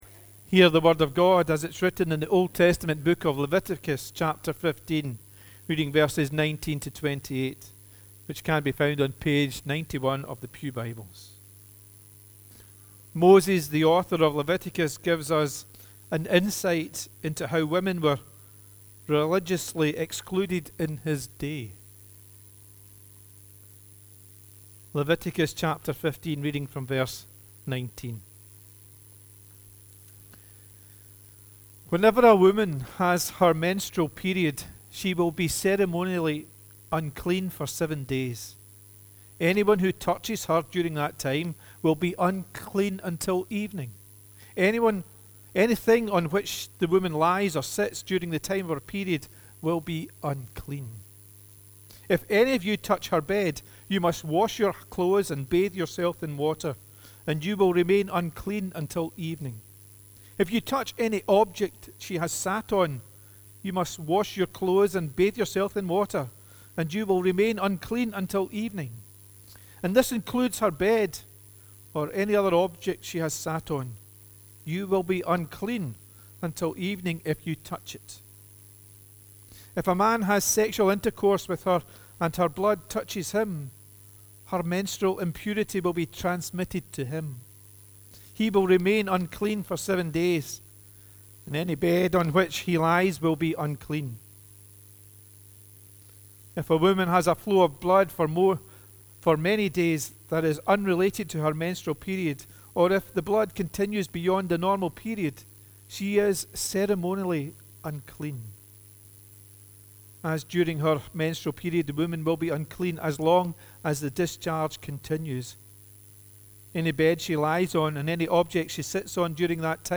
The readings prior to the sermon are Leviticus 15: 19-28 and Mark 5: 21-36